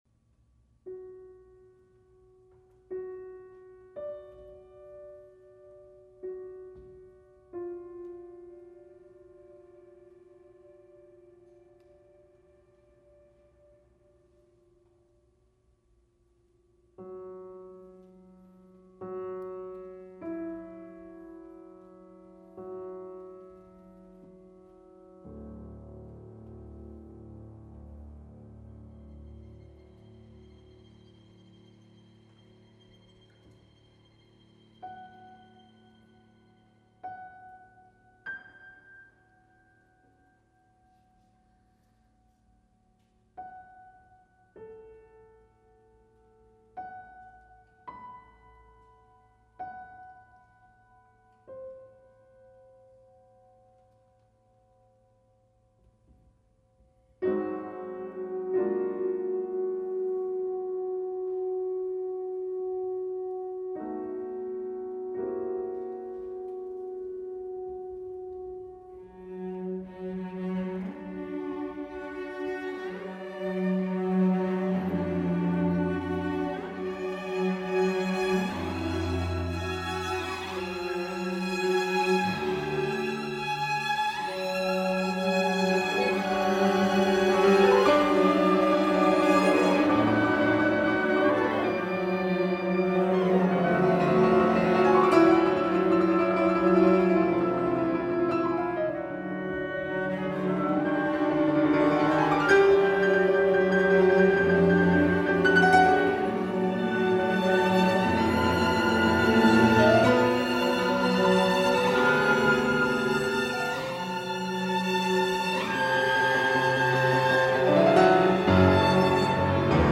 for piano and Orchestra
Piano